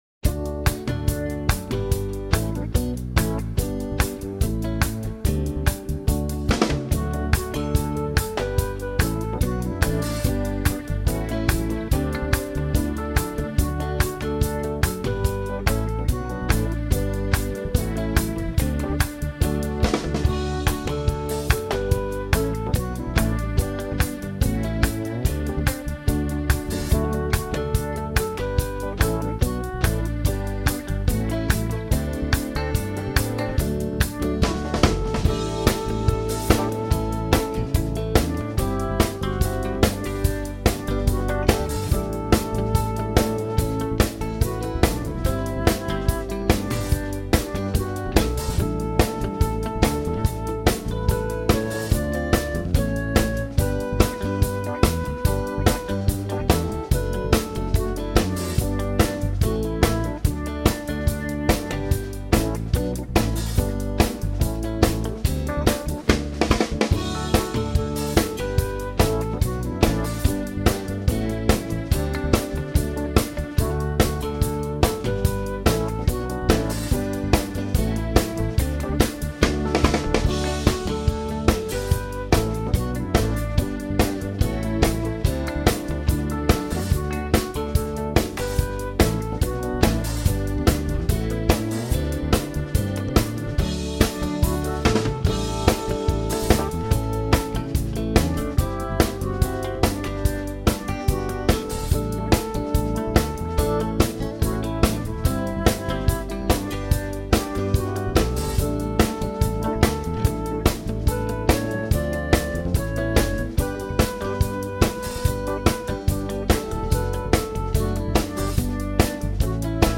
a song of praise
I have ignored the key change for the last verse.